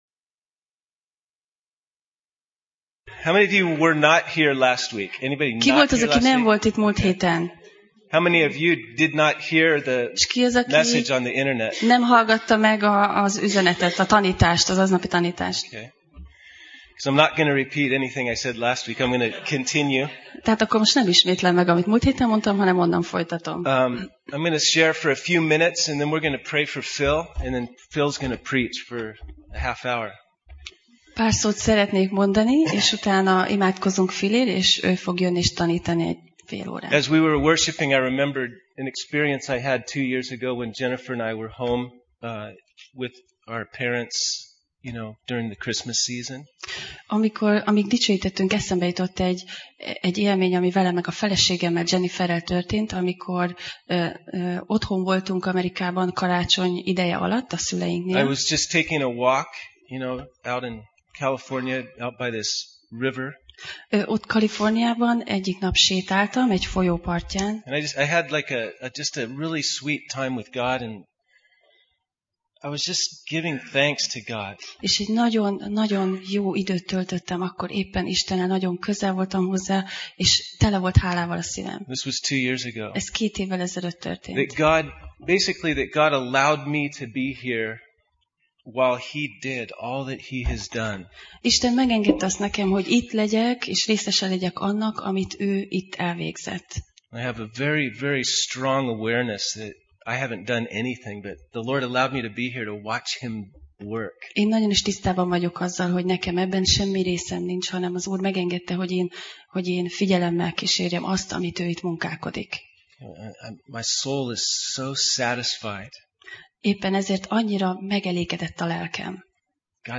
Sorozat: Tematikus tanítás
Alkalom: Vasárnap Reggel